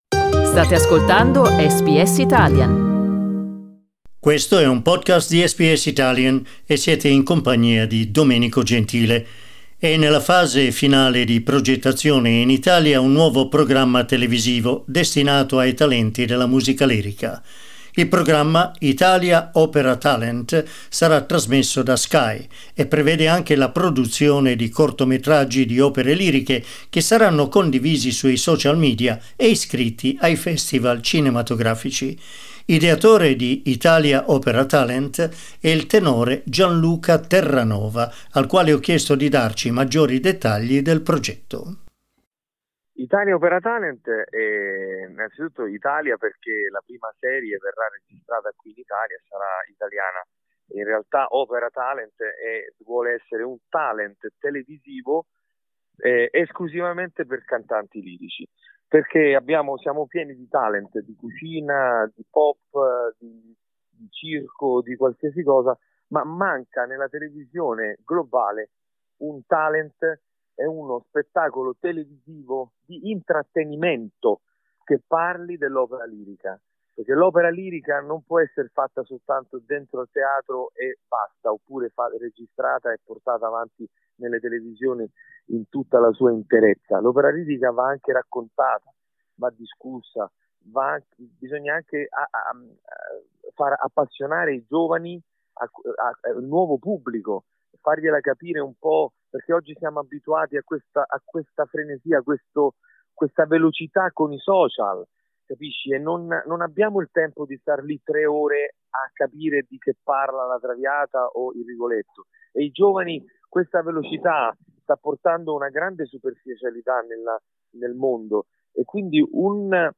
Ascolta l'intervista: LISTEN TO "Italia Opera Talent" per avvicinare i giovani alla lirica SBS Italian 12:46 Italian Le persone in Australia devono stare ad almeno 1,5 metri di distanza dagli altri.